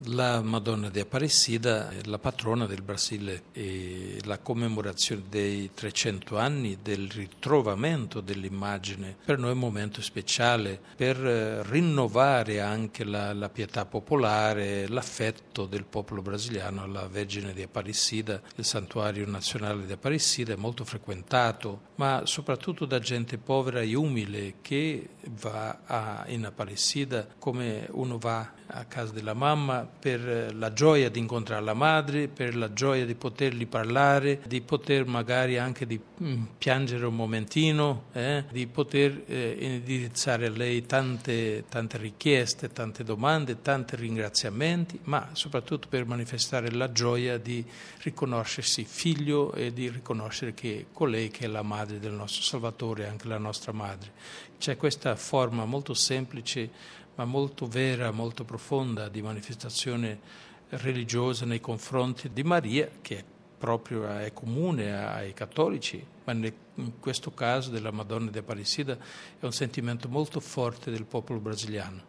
Ascolta e scarica il podcast dell'intervista al card. Scherer